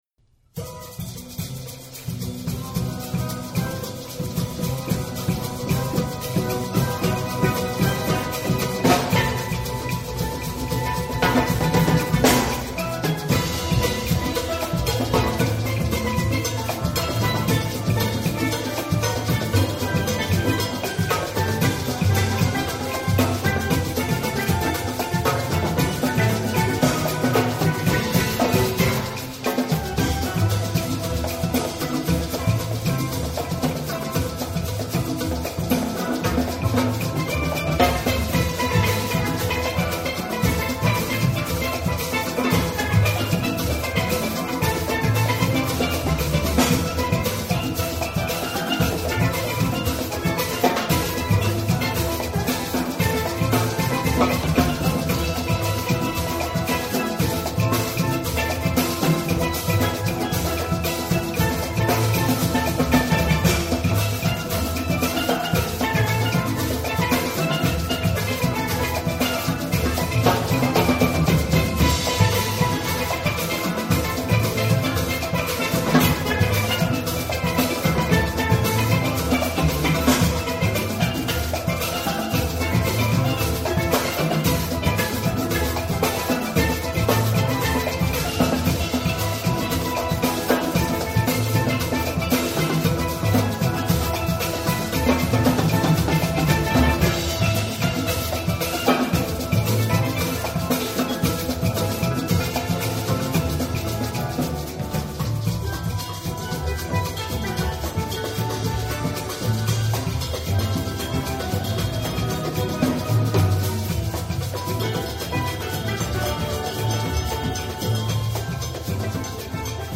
Snare and Bass Drum Level